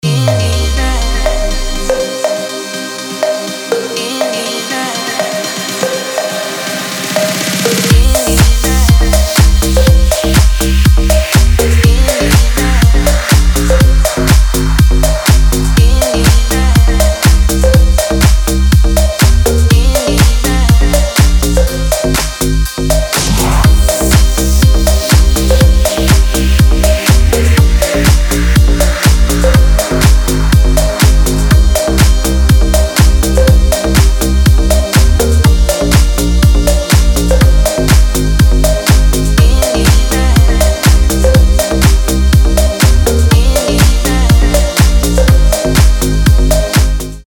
• Качество: 320, Stereo
dance
спокойные
приятные
Стиль: deep house